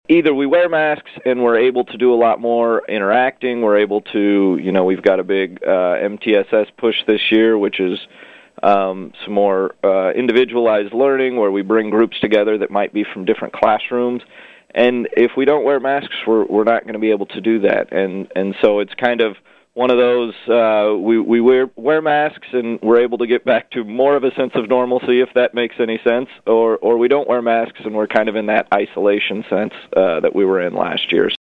School administrators were part of separate interviews on KVOE the past few days to update their situations.